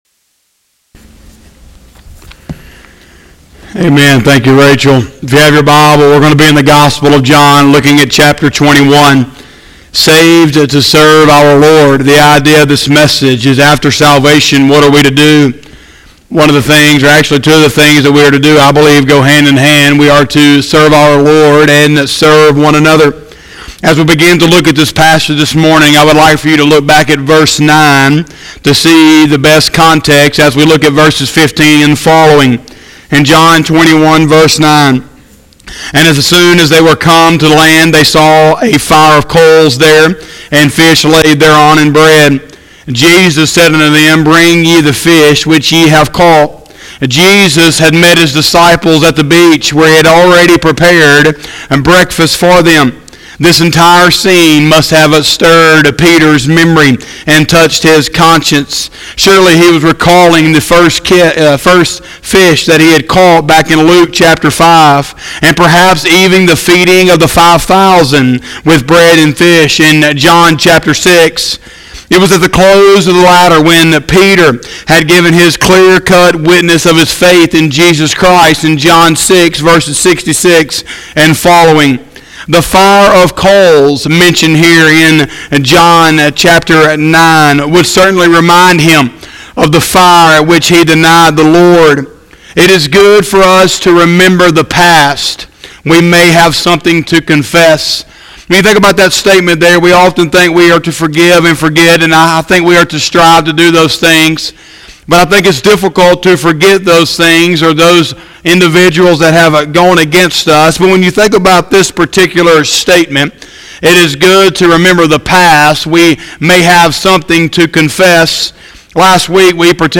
04/19/2020 – Sunday Morning Service